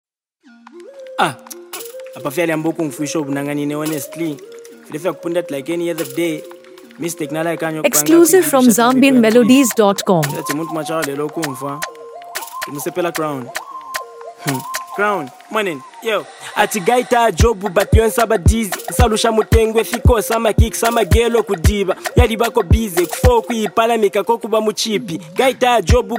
For those looking to enjoy real Zambian rap at its finest